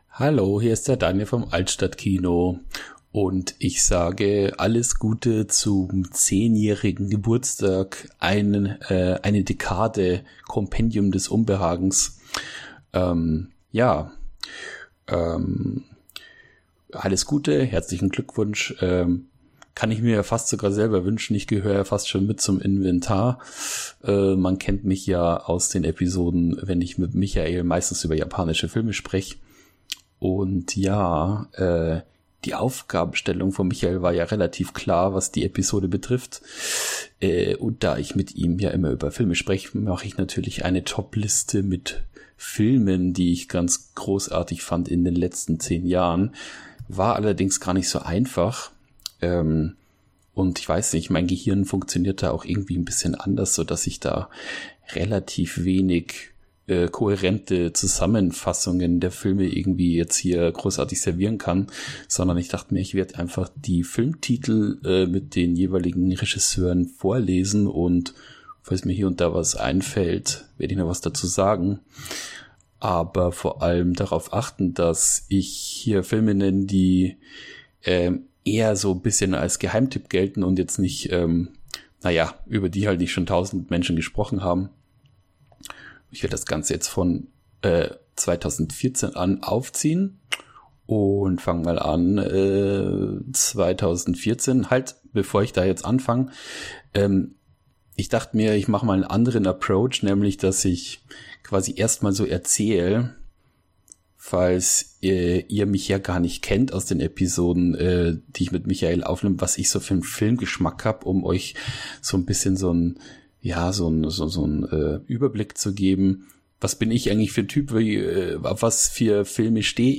Geburtstagsspezial Auch in Teil 2 sind wieder Menschen zusammengekommen, um mit uns unseren Geburtstag zu feiern. Es wird doof und albern, ein wenig informativ und vielleicht auch schön.